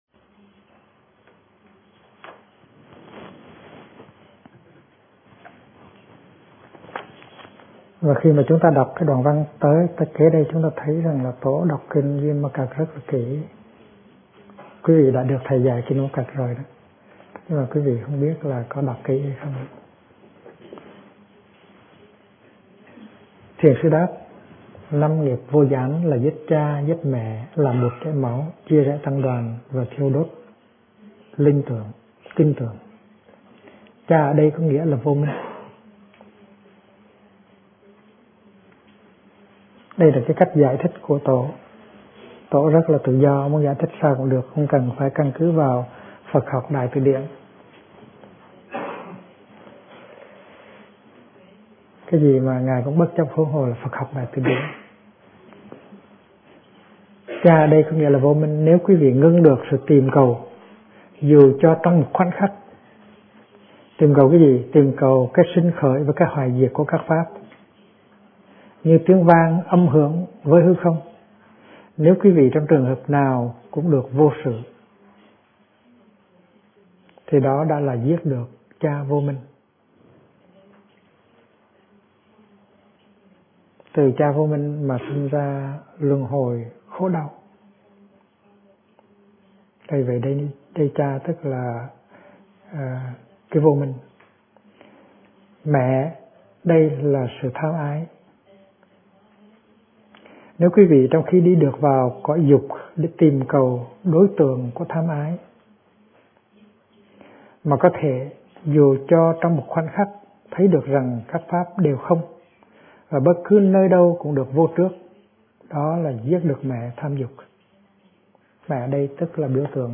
Kinh Giảng Năm Nghiệp Vô Gián - Thích Nhất Hạnh
Năm Nghiệp Vô Gián - Thầy Thích Nhất Hạnh thuyết giảng